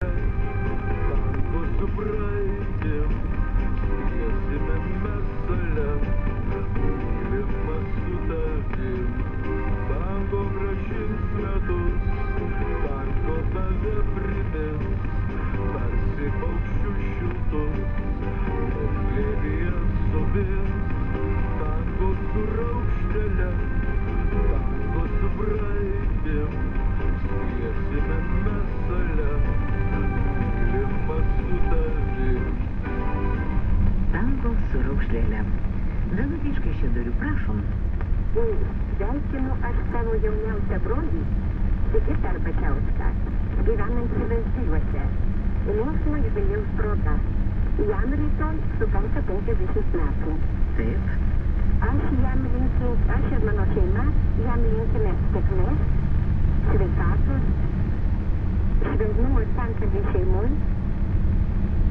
I'm using sound samples of radio static that I recorded last June while on the island of Gotland in southern Sweden.
Static1-2.wav